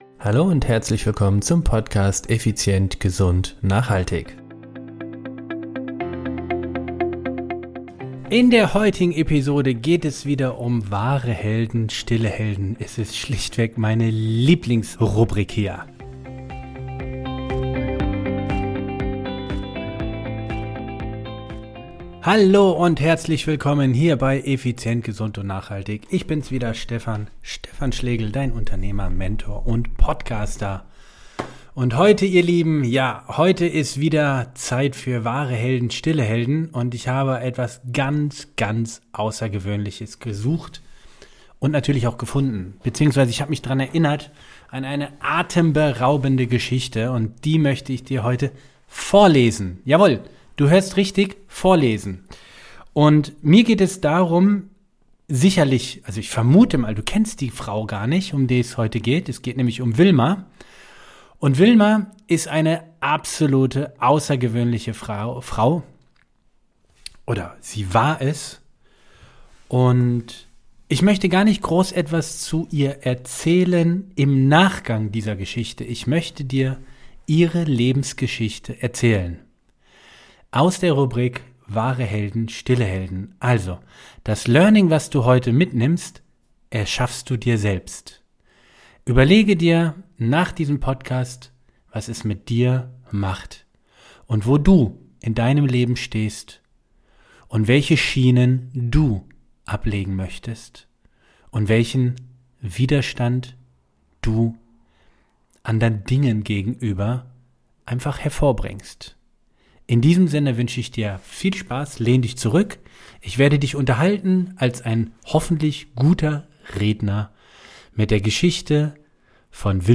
Nur eine Geschichte. Vorgelesen.
Meine erste vorgelesene Geschichte im Podcast.